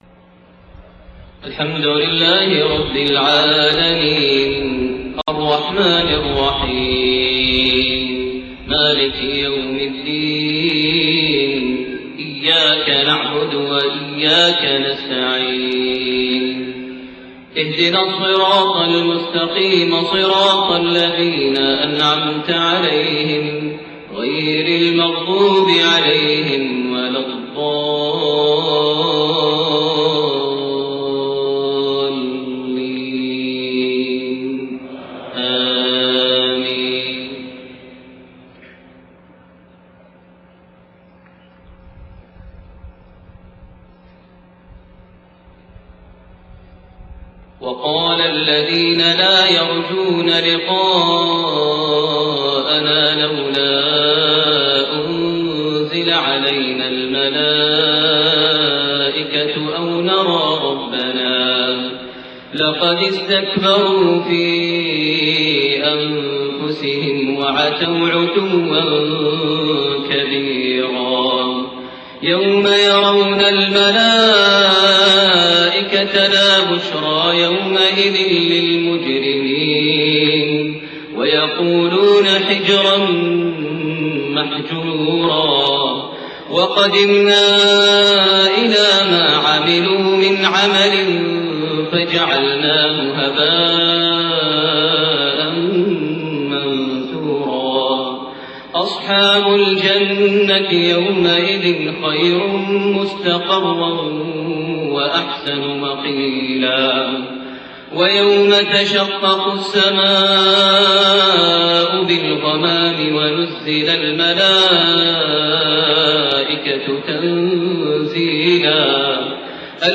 صلاة المغرب9-2-1431 من سورة الفرقان21-31 > 1431 هـ > الفروض - تلاوات ماهر المعيقلي